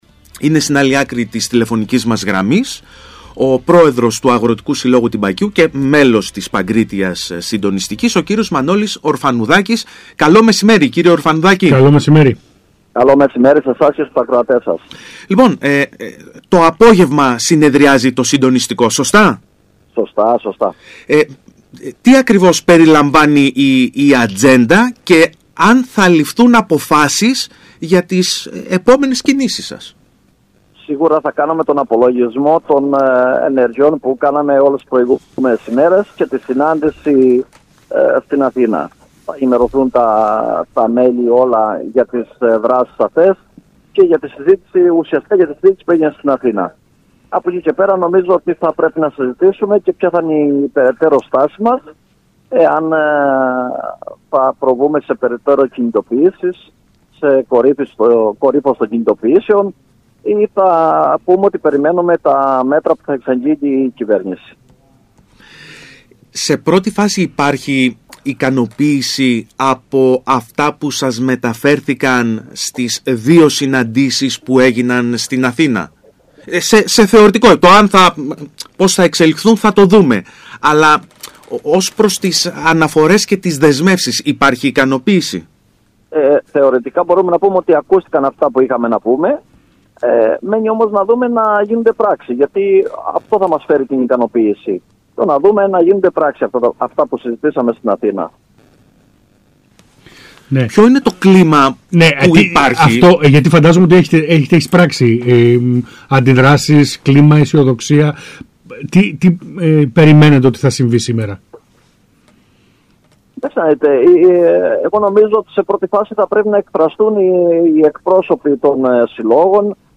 Σύμφωνα με τα όσα δήλωσε στον ΣΚΑΪ Κρήτης